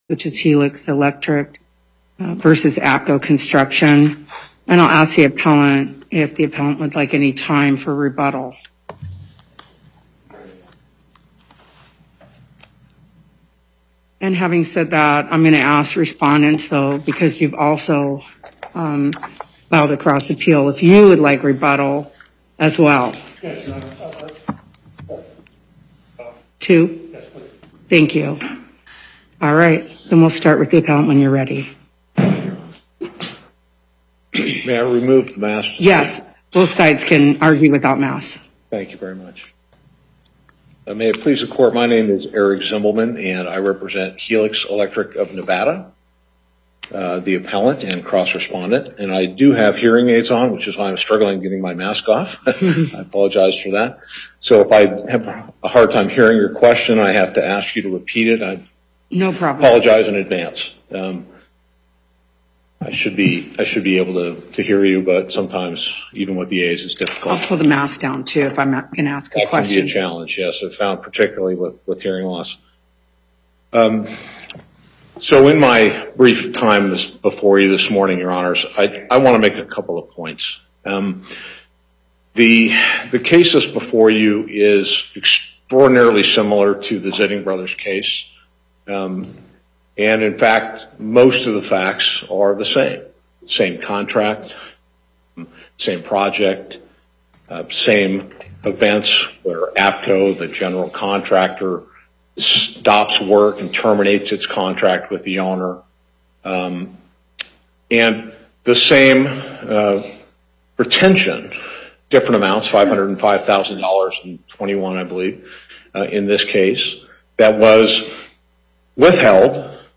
Loading the player Download Recording Docket Number(s): 77320 Date: 12/02/2021 Time: 10:00 A.M. Location: Carson City Before the Southern Nevada Panel, Justice Silver presiding.